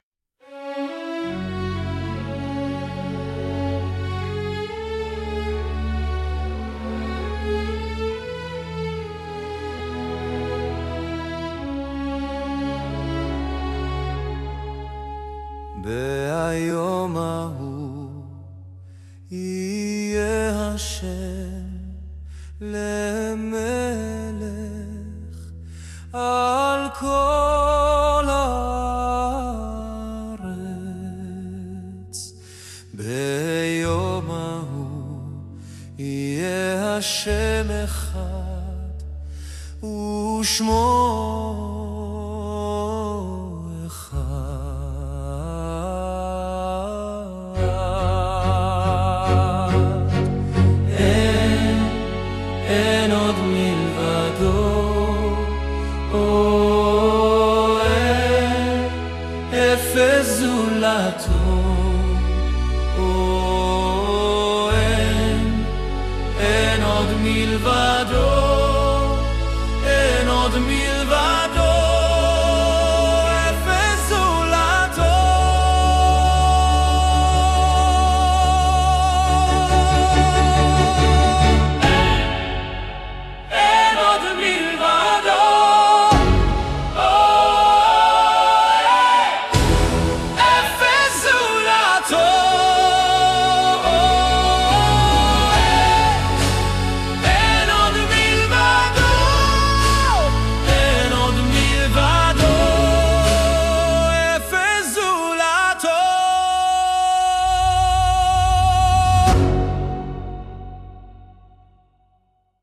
הסאונד לא משובח במיוחד אבל סה’'כ נחמד…